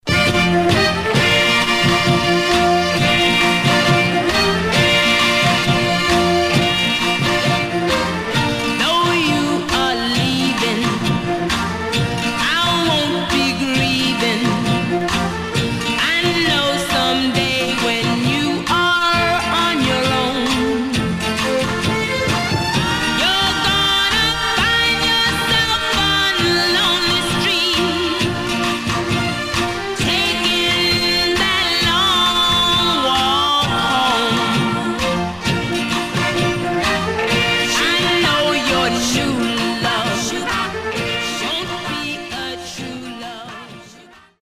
Stereo/mono Mono
White Teen Girl Groups